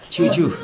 [t͡ɕut͡ɕu] noun honey